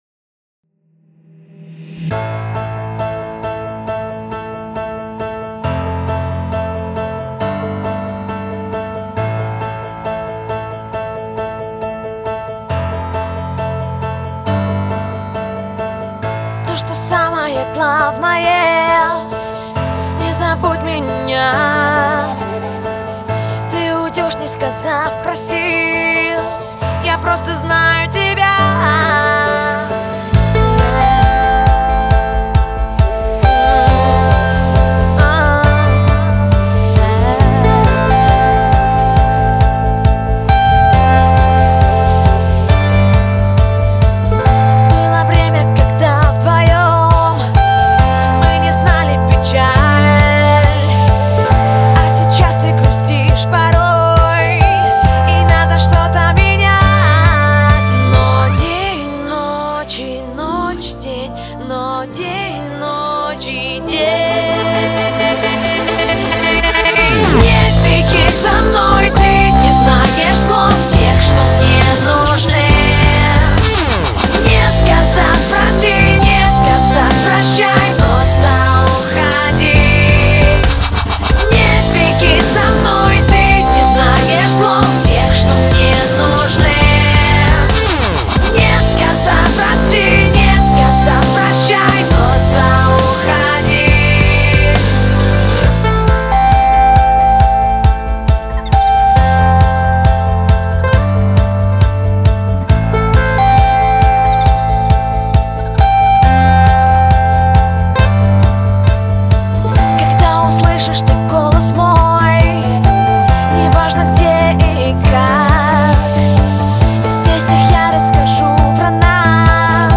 Категория: Dubstep 2016